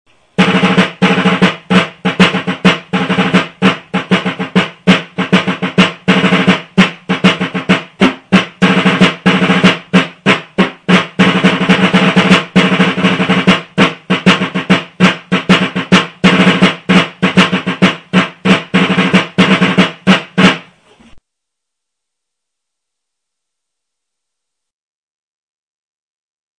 Trommelsignale
(Drummer & Fifer)
Wichtige Trommelsignale und ein paar Rhythmen -